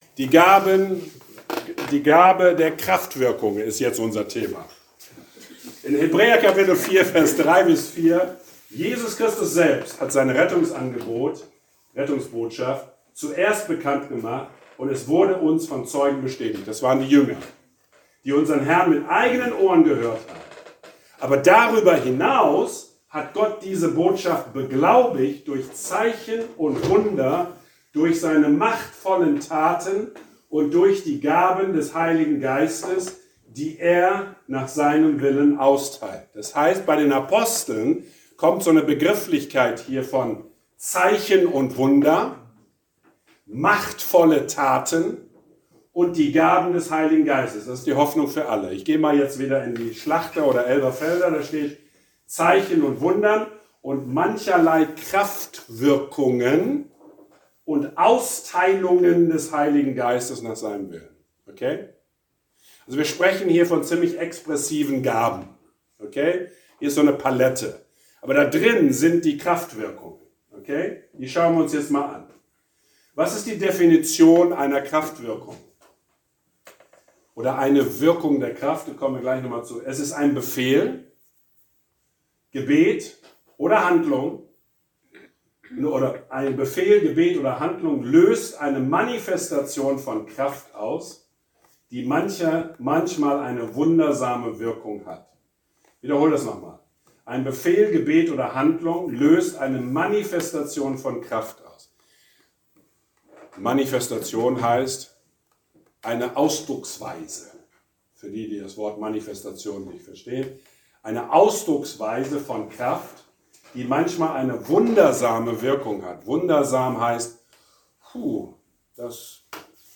Predigtreihe: "David" - Friedenskirche Buchholz